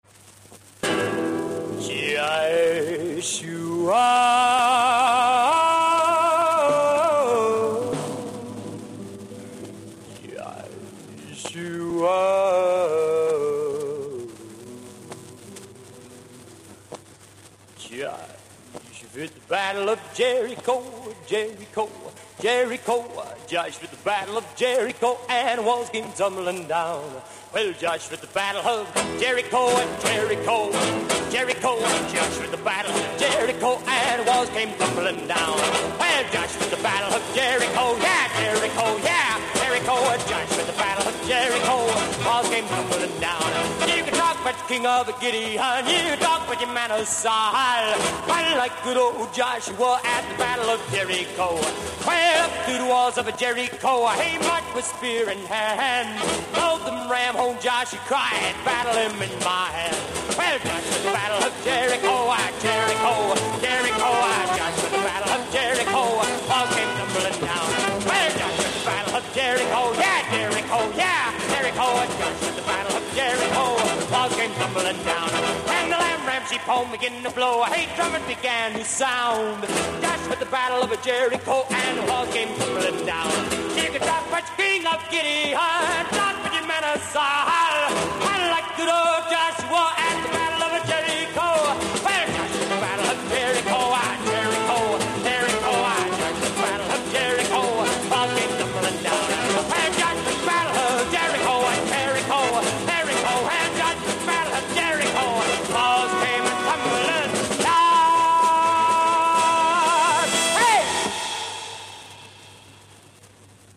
И несколько известных песен в исполнении Дина Рида.